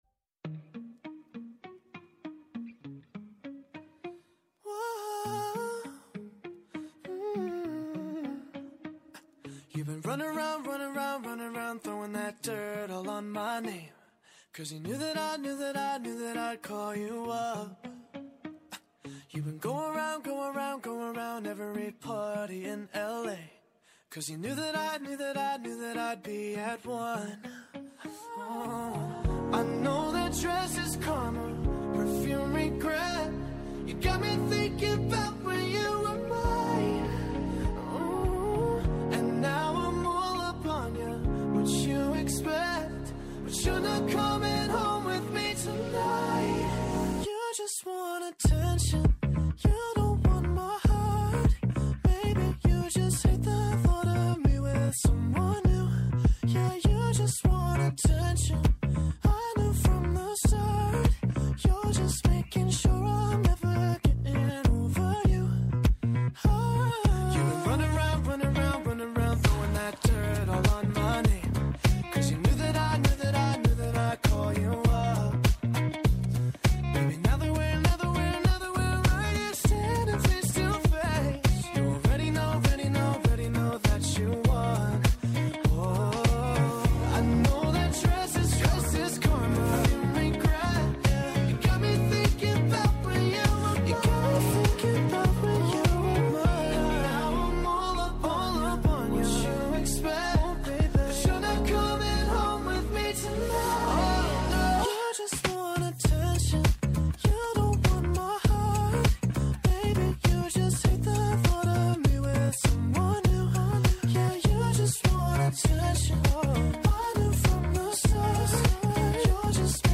Καλεσμένοι: ο αντιπεριφερειάρχης Κορίνθου Χάρης Βιτινιώτης